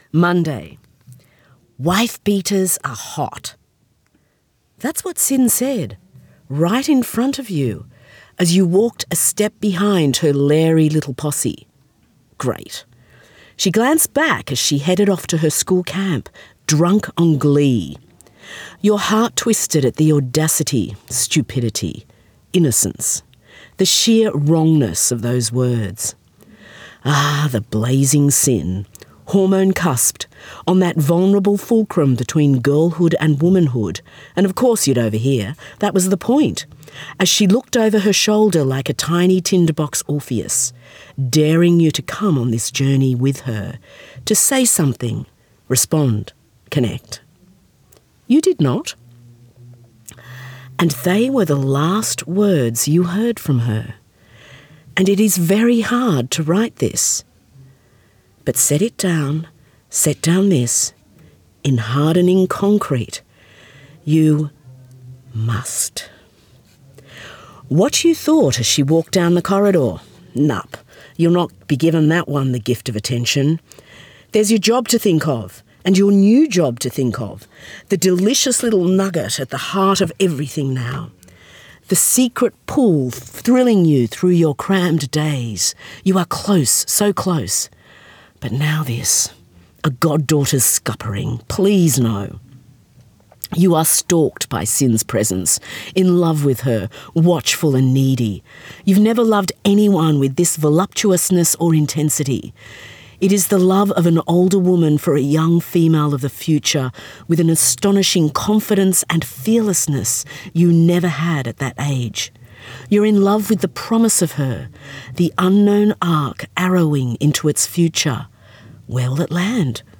Recorded at Bellingen Readers and Writers Festival